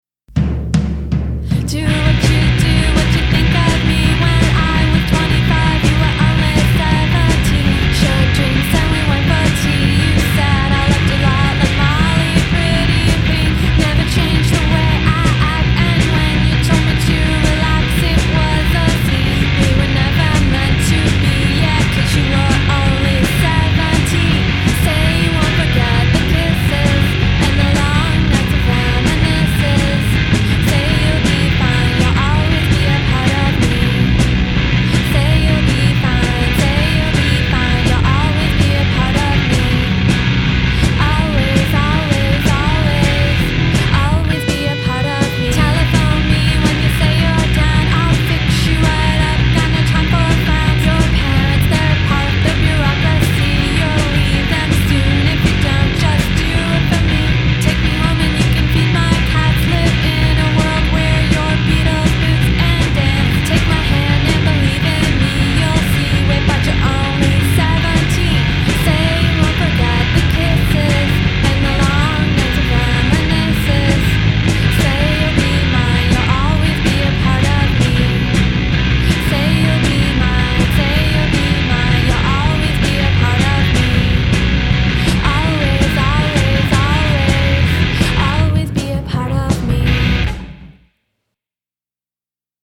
Today we have a live recording of